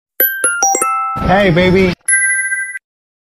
Genre: Nada dering Korea